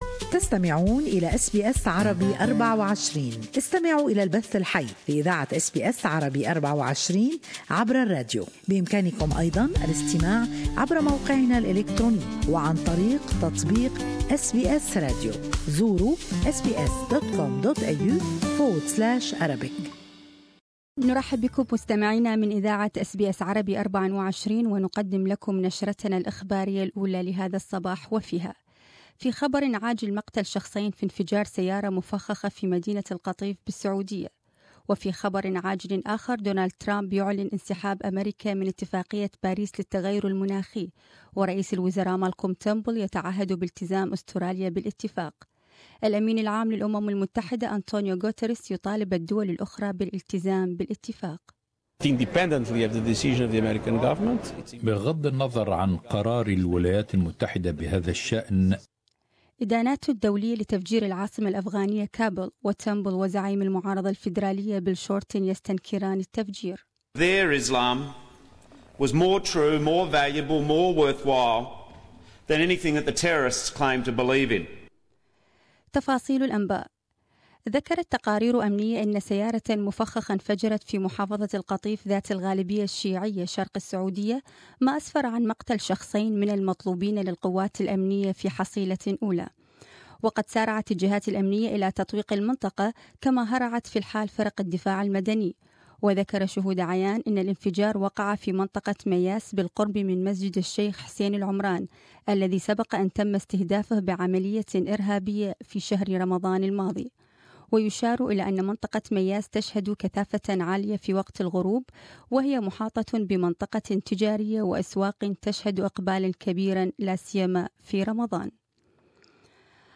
Morning news bulletin.